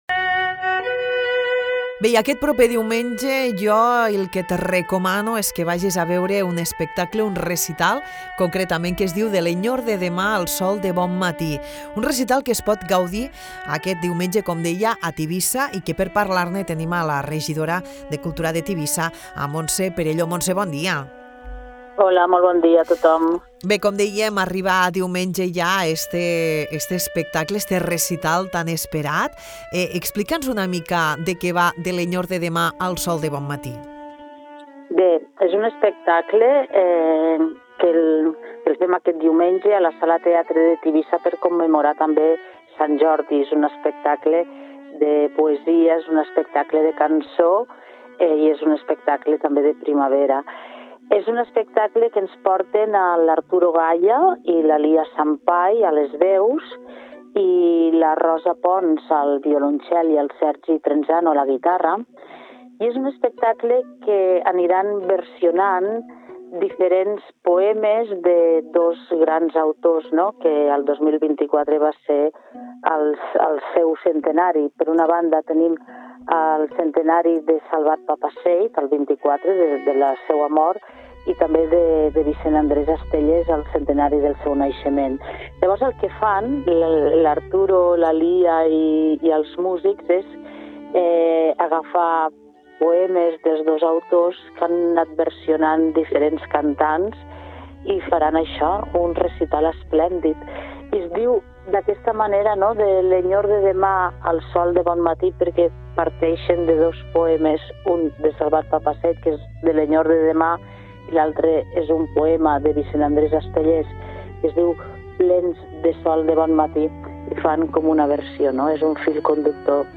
Parlem amb Montse Perelló, regidora de cultura de Tivissa